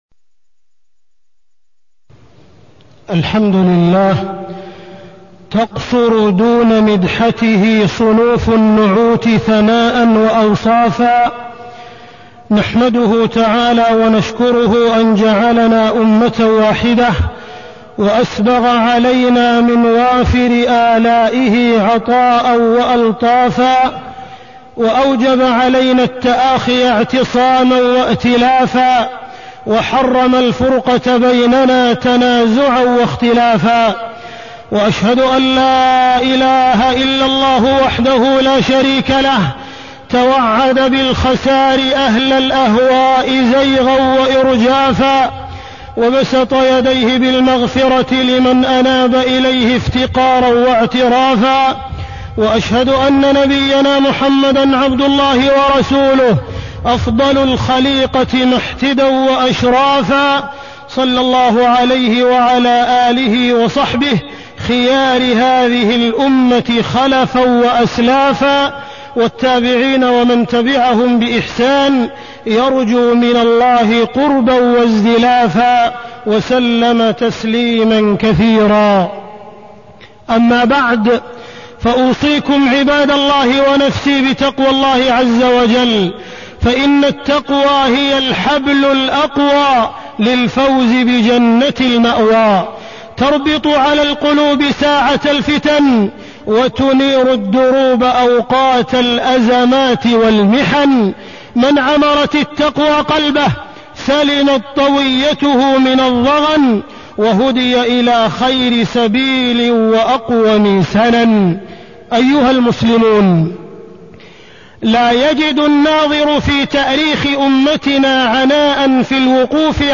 تاريخ النشر ٣ ذو القعدة ١٤٢٤ هـ المكان: المسجد الحرام الشيخ: معالي الشيخ أ.د. عبدالرحمن بن عبدالعزيز السديس معالي الشيخ أ.د. عبدالرحمن بن عبدالعزيز السديس الحذر من سوء الظن